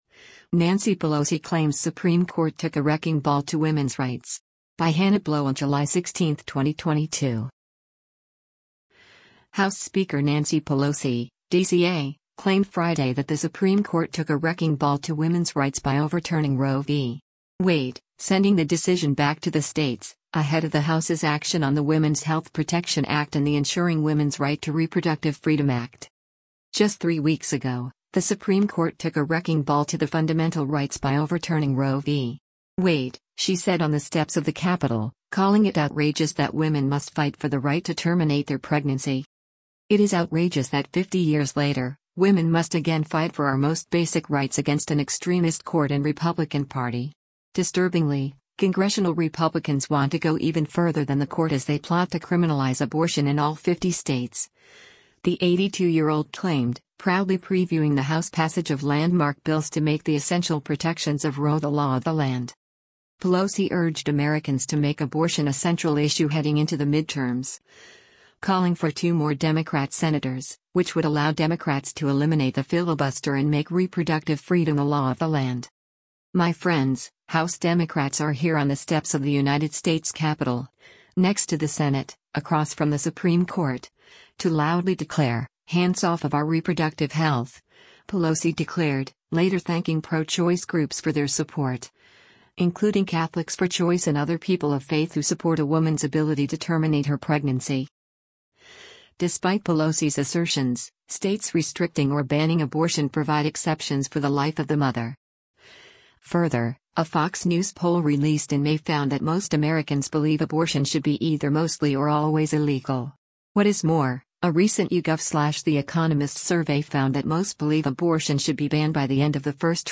House Speaker Nancy Pelosi of California, accompanied by female House Democrats, speaks at
“Just three weeks ago, the Supreme Court took a wrecking ball to the fundamental rights by overturning Roe v. Wade,” she said on the steps of the Capitol, calling it “outrageous” that women must fight for the “right” to terminate their pregnancy.